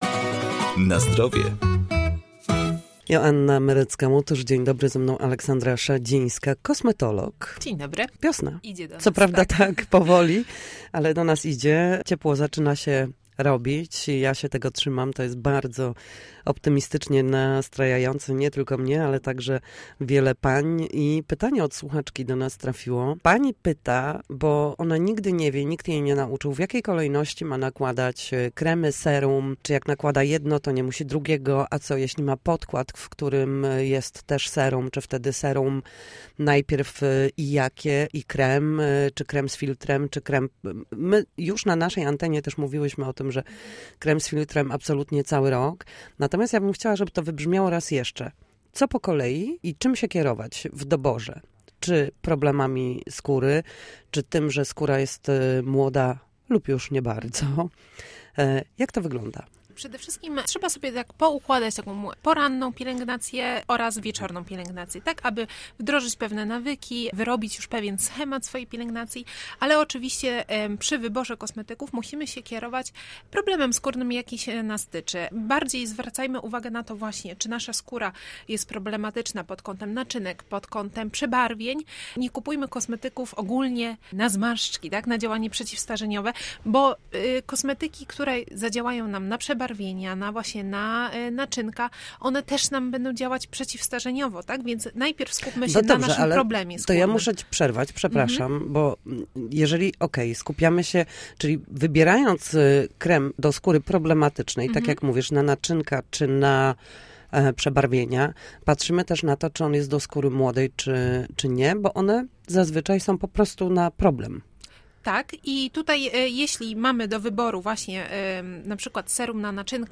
W poniedziałki o godzinie 7:20, a także po 14:30 na antenie Studia Słupsk dyskutujemy o tym, jak wrócić do formy po chorobach i urazach.